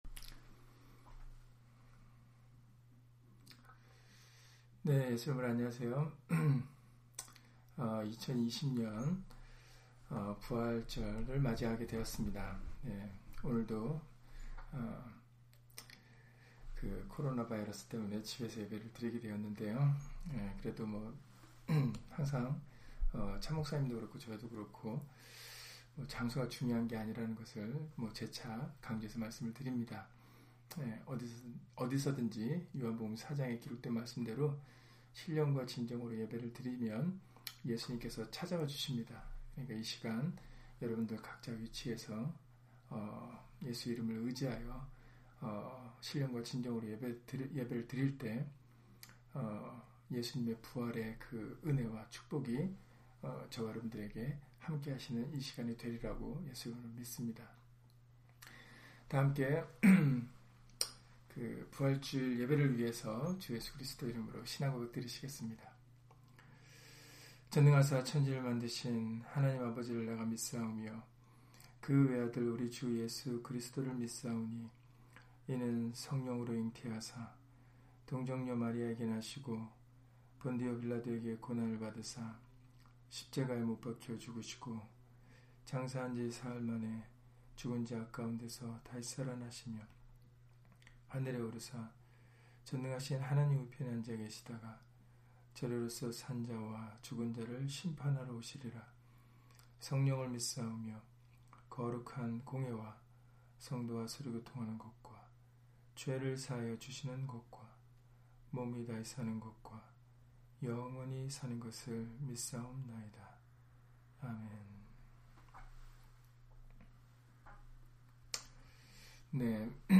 사도행전 13장 35-37절 [부활 주일] - 주일/수요예배 설교 - 주 예수 그리스도 이름 예배당